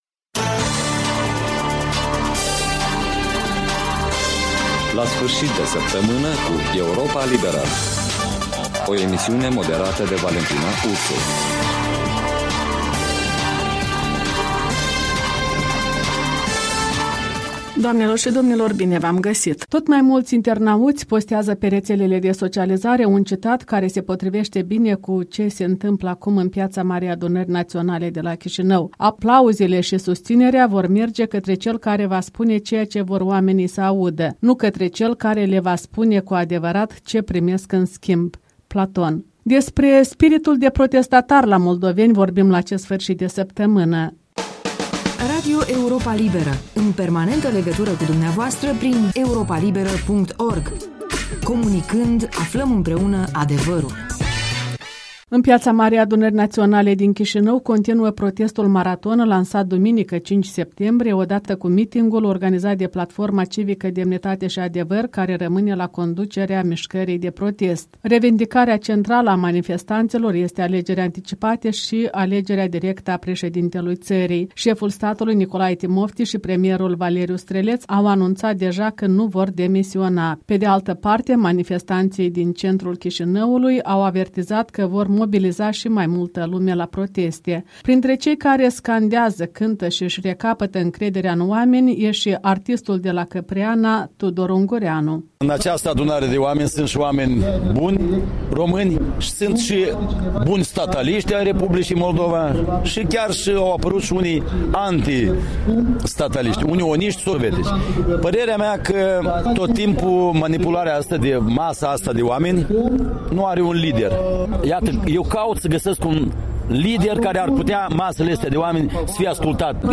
printre protestatarii din centrul Chișinăului